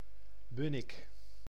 Bunnik (Dutch pronunciation: [ˈbʏnɪk]
Nl-Bunnik.ogg.mp3